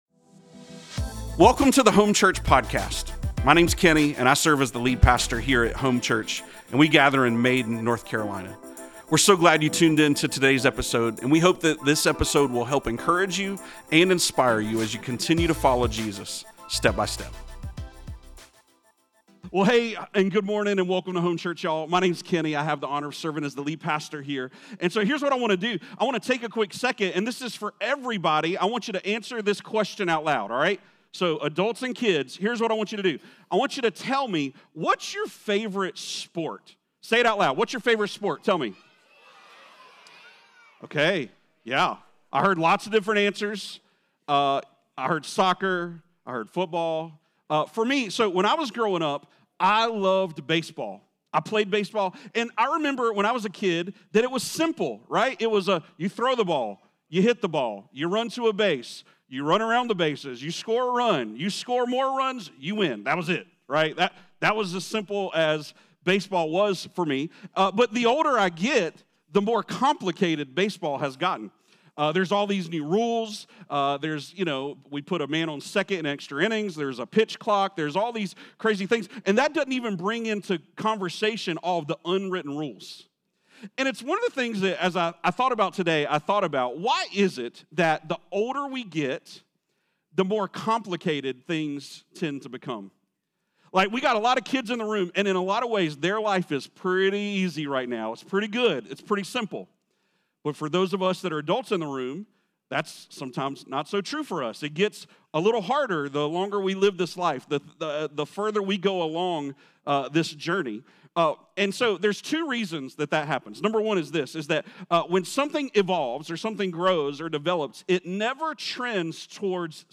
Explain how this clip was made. Join us for our first ever Family Gathering, a brand new tradition at Home Church that invites all areas of our Church to join together in one large corporate gathering.